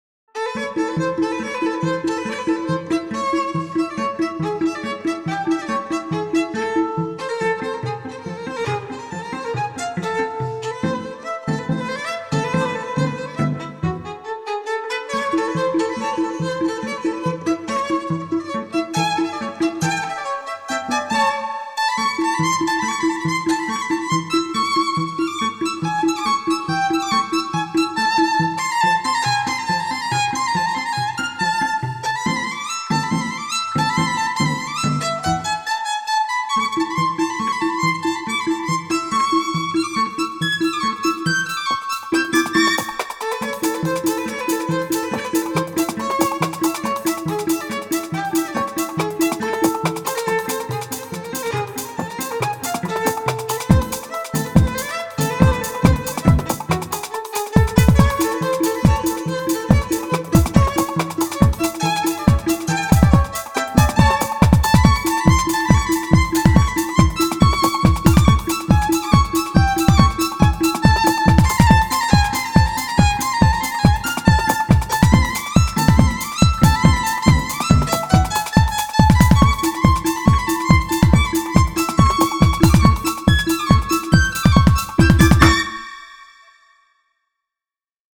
D-Kabalevskiy-Klouny-strunnyy-duet-ArtMix-2003-AVe.mp3